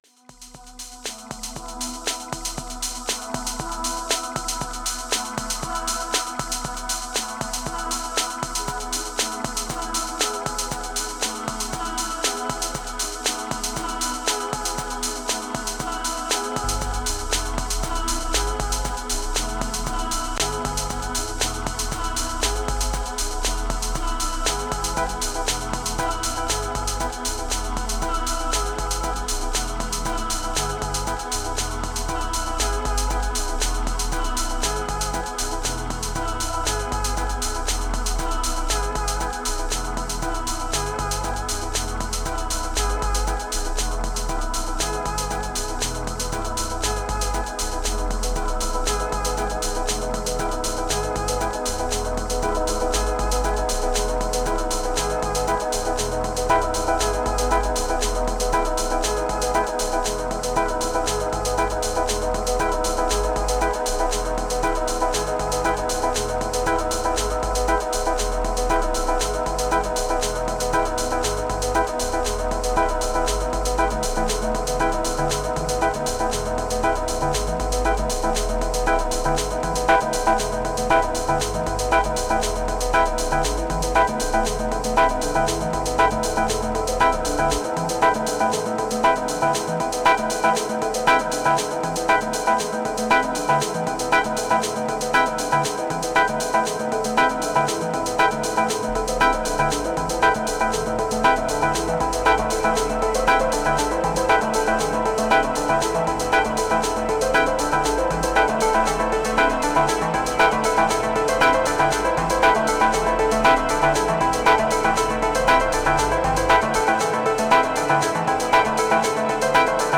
127📈 - 91%🤔 - 118BPM🔊 - 2025-12-21📅 - 335🌟